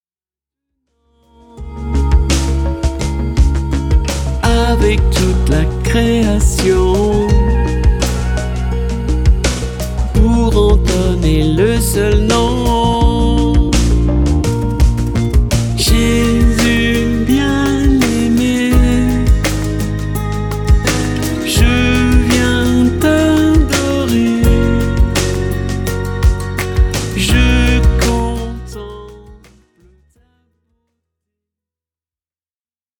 Une pop-Louange actuelle à la fois profonde et dansante